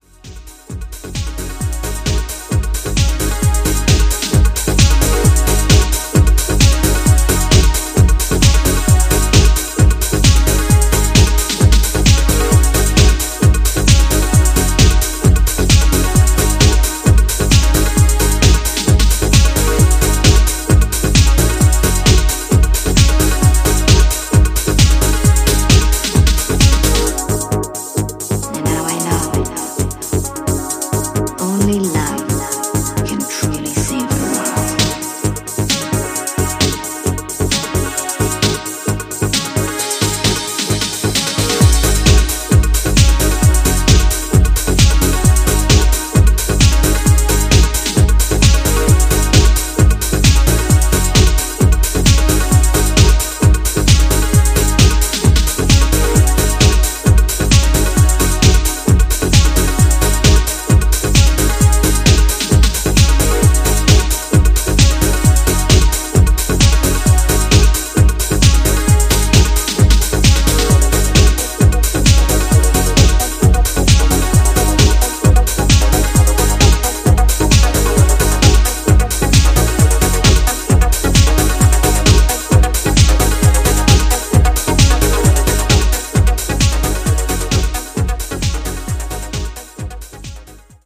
いずれの楽曲もソリッドでカラフル、そしてポジティヴなパワーで溢れたピークタイム・チューン！